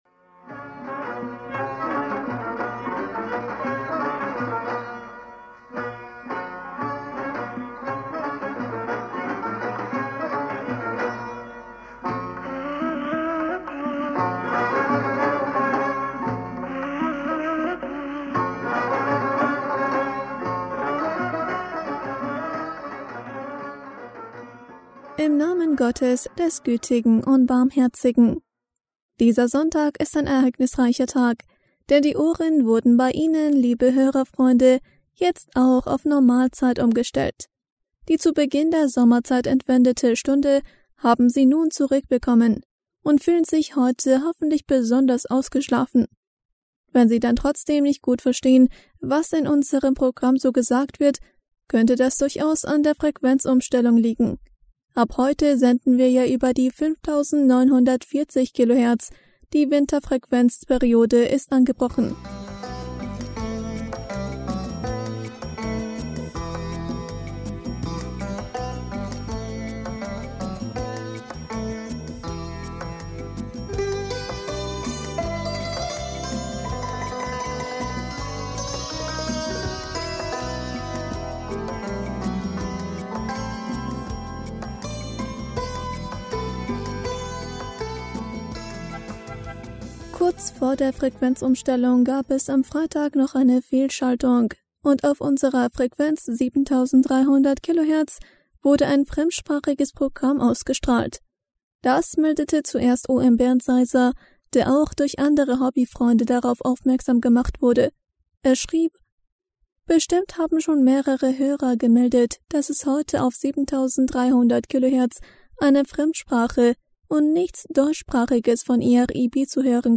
Hörerpostsendung am 25. Oktober 2020 Bismillaher rahmaner rahim - Dieser Sonntag ist ein ereignisreicher Tag, denn die Uhren wurden bei Ihnen liebe Hörer...